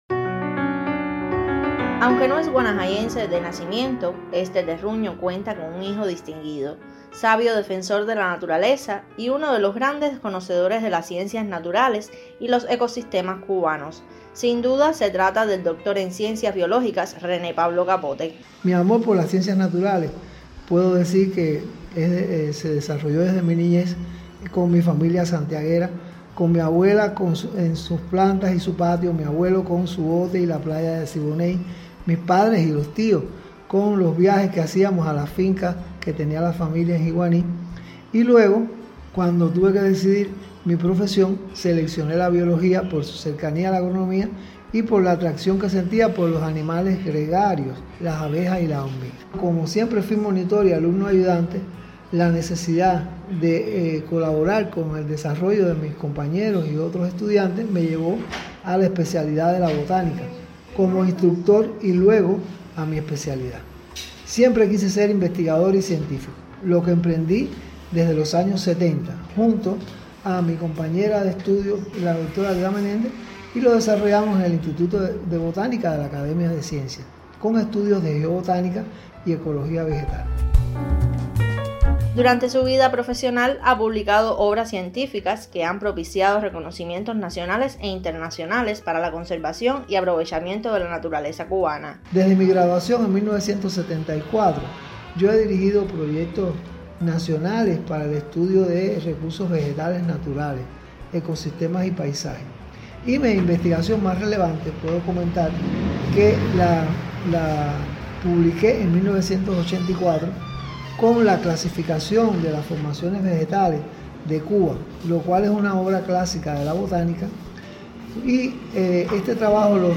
Sabio defensor de la naturaleza (+Audio) . Entrevista